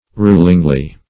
rulingly - definition of rulingly - synonyms, pronunciation, spelling from Free Dictionary Search Result for " rulingly" : The Collaborative International Dictionary of English v.0.48: Rulingly \Rul"ing*ly\, adv.